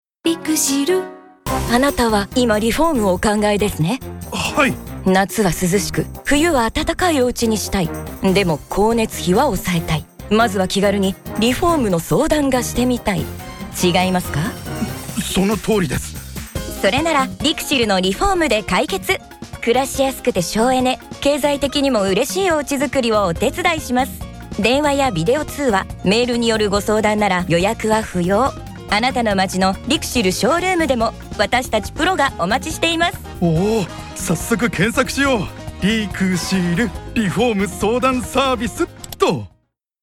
今回のCMでは、その相談しやすさをフレンドリーかつコミカルな演出で表現しました。